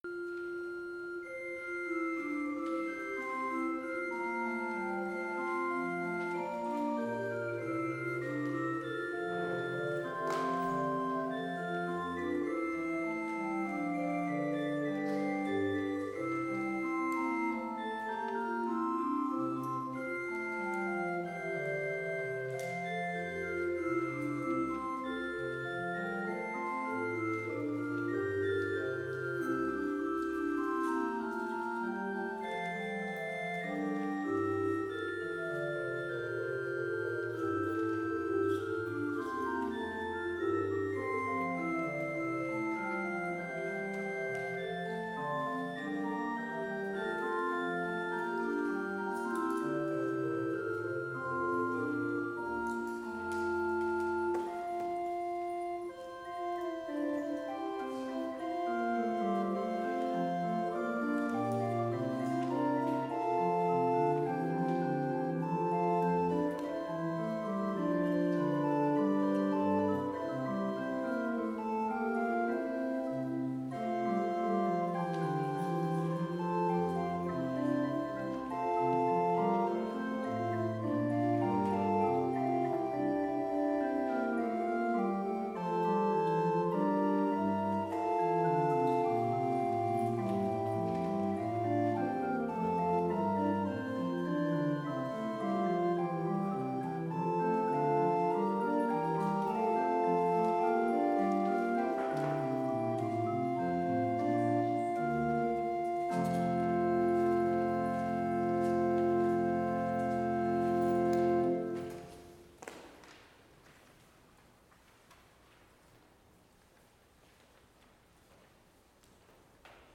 Complete service audio for Chapel - April 27, 2021